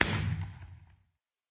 blast_far1.ogg